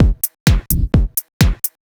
Electrohouse Loop 128 BPM (17).wav